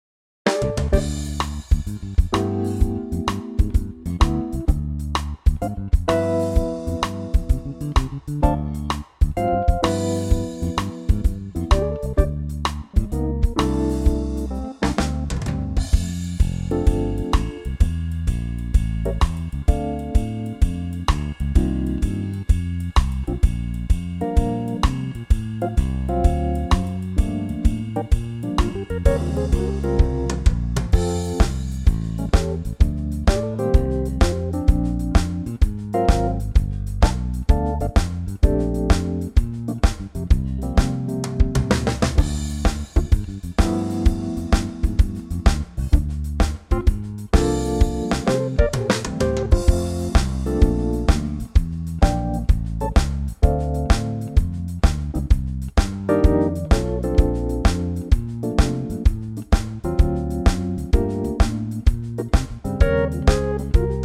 Unique Backing Tracks
key - F - vocal range - C to D
A super cool swinging arrangement